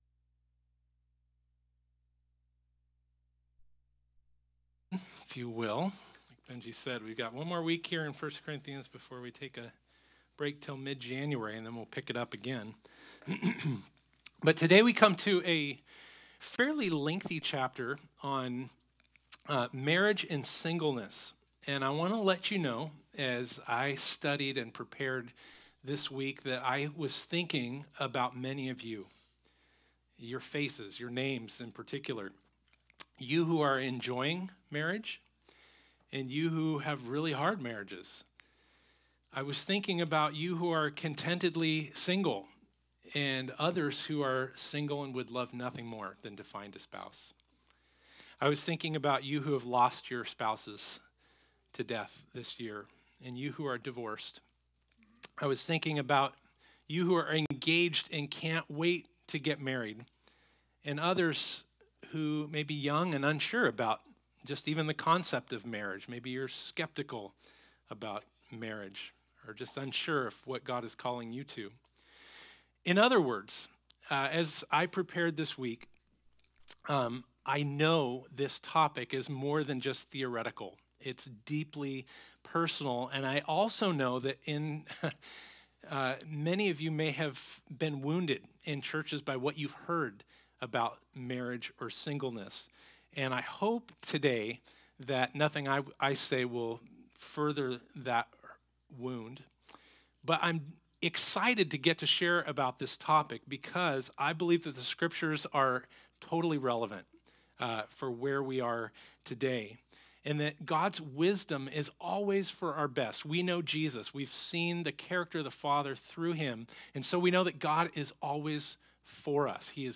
1 Corinthians Passage: 1 Corinthians 7 Service Type: Sunday Topics